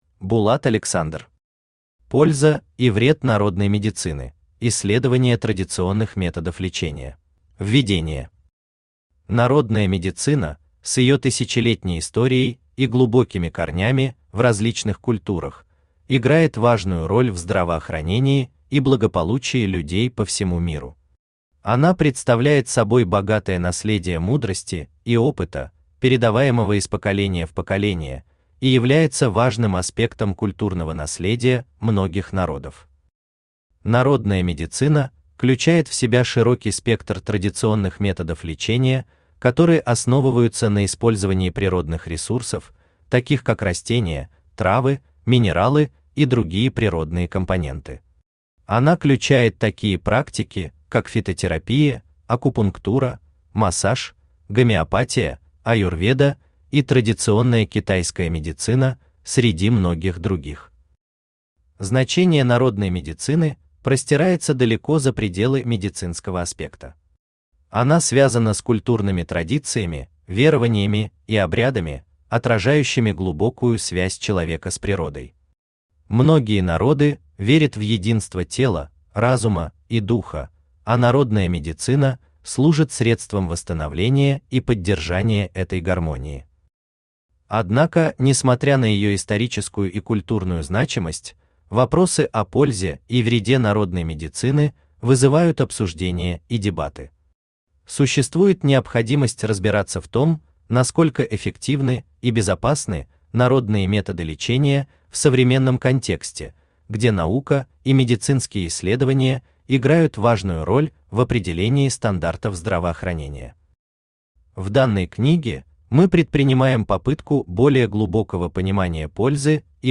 Аудиокнига Польза и вред народной медицины: исследование традиционных методов лечения | Библиотека аудиокниг
Aудиокнига Польза и вред народной медицины: исследование традиционных методов лечения Автор Булат Александр Читает аудиокнигу Авточтец ЛитРес.